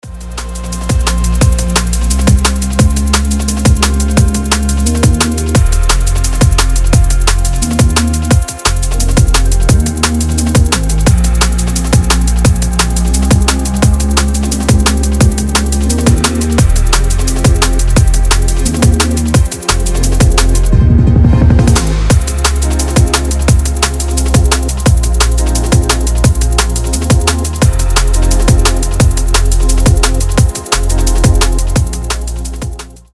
мелодичные
Liquid DnB
драм энд бейс
Стиль: liquid drum&bass, liquid funk